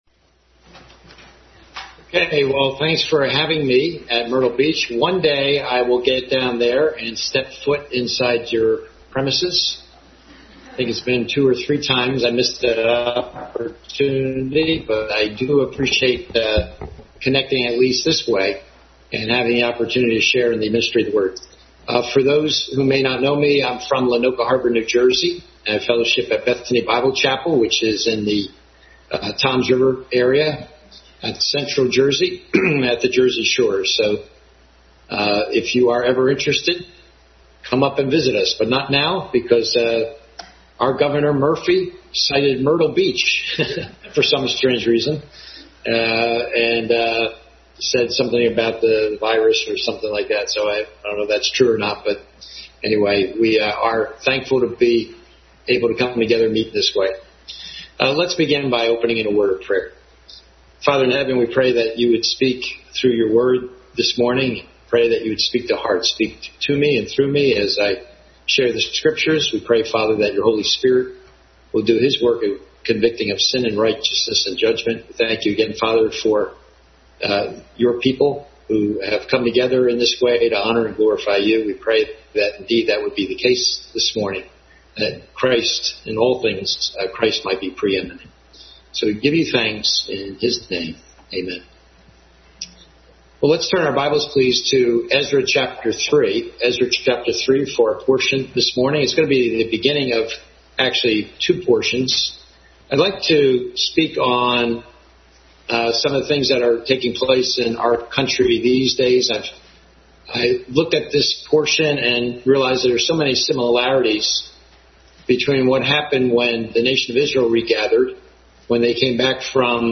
Bible Text: Ezra 3:1-13, Haggai 1:1-15 | Family Bible Hour Message.